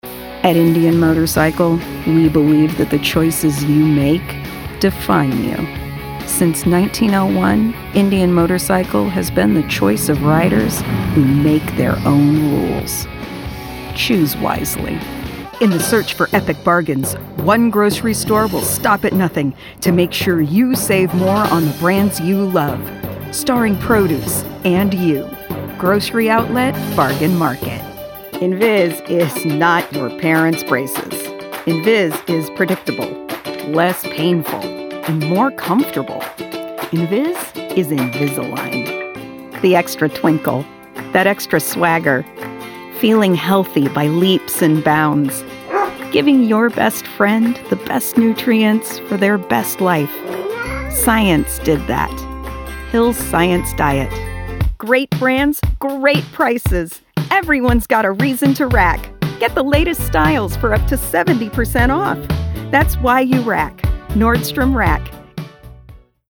Voice - Stage - Narration
Voiceover Demo